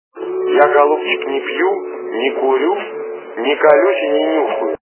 » Звуки » Люди фразы » Из к/ф - Не курю, не пью...
При прослушивании Из к/ф - Не курю, не пью... качество понижено и присутствуют гудки.